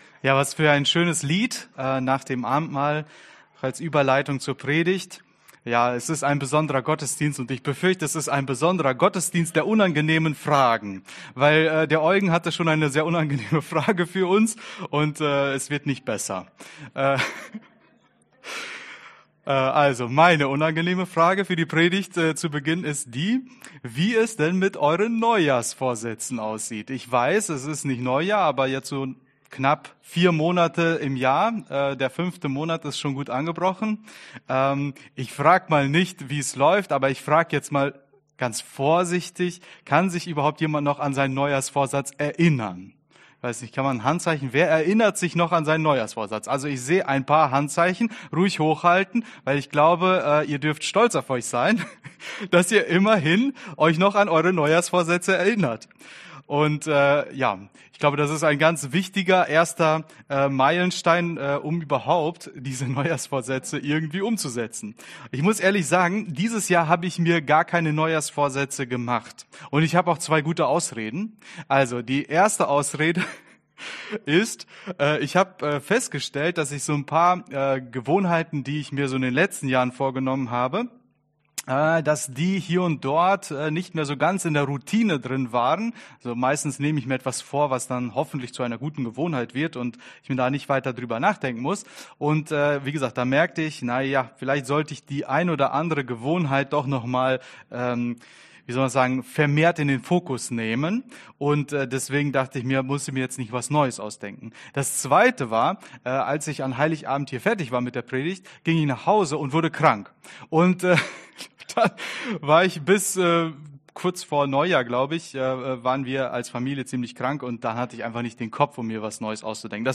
Mai 2025 Aufblühen in der Wüste Prediger